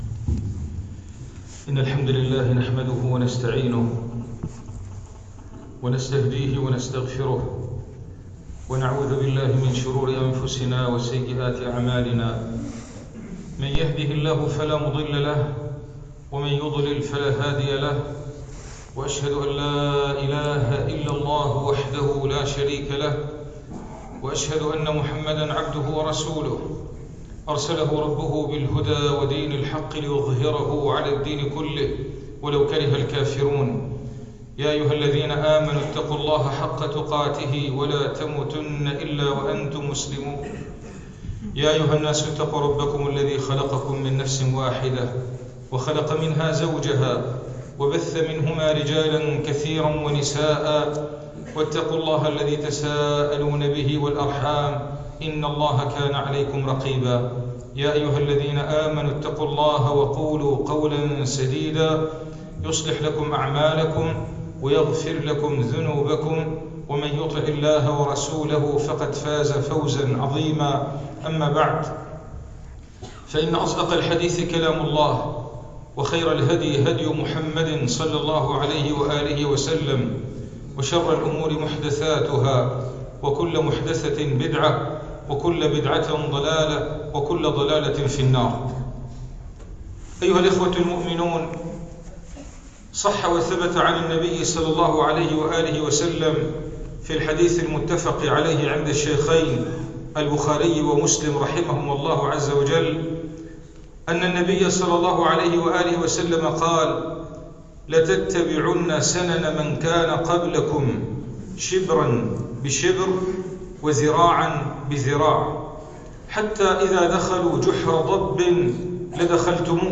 [منبر الجمعة]
المكان : المسجد البحري